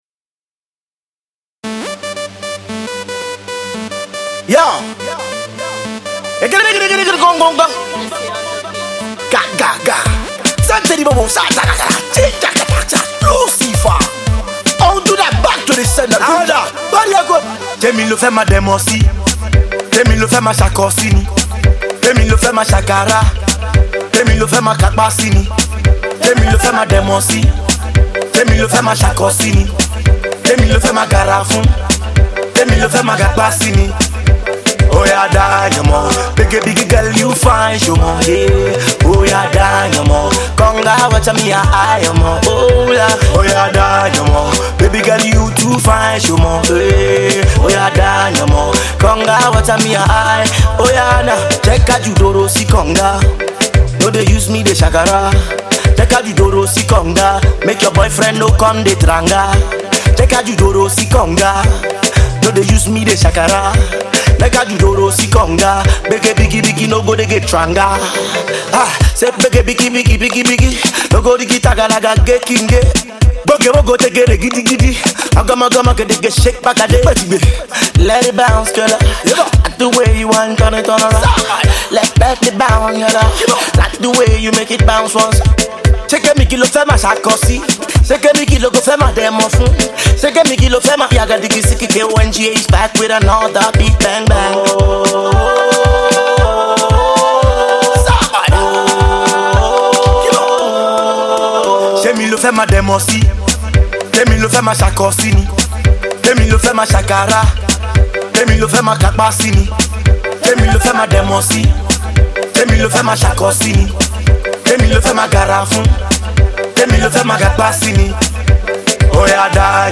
Pop, Yoruba Music 0 35 …